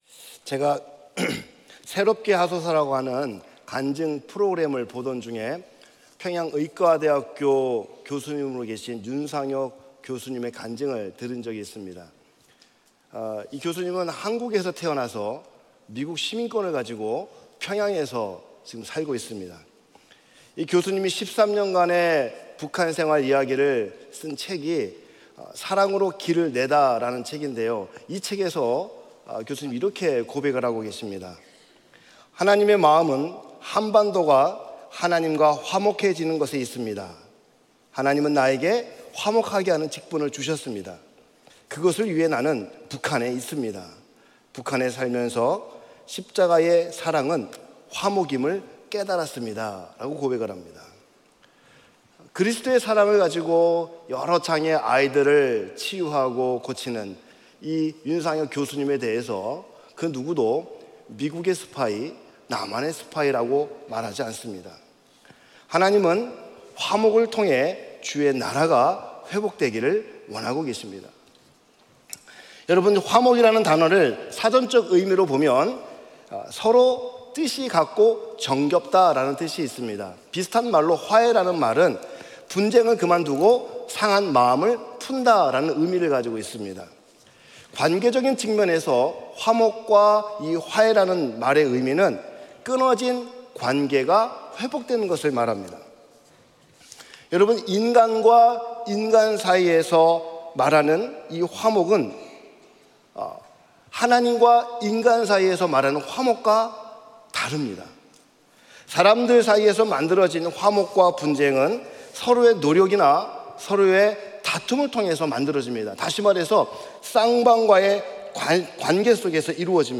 2024년 고난주간 특별새벽기도회 둘째날 | 십자가로 회복 – 화목 (3/26/2024)